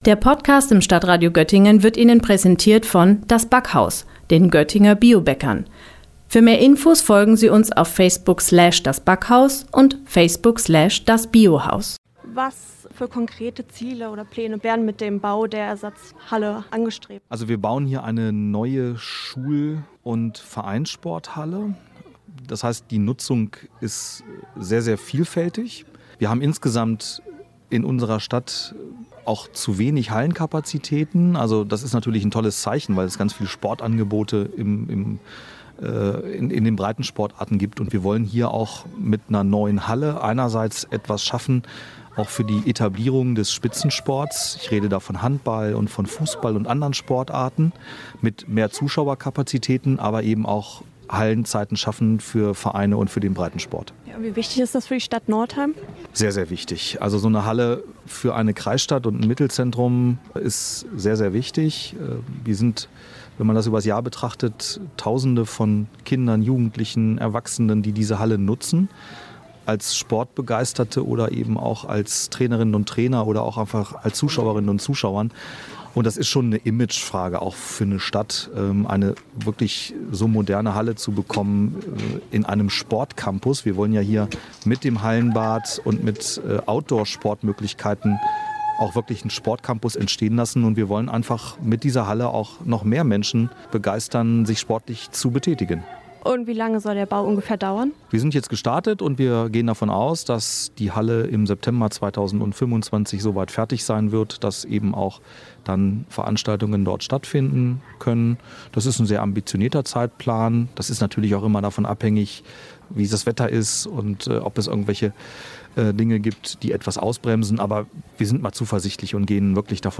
Am 04. März war es soweit und der offizielle Spatenstich des Ersatzbaues "Sporthalle am Schuhwall' in Northeim hat stattgefunden.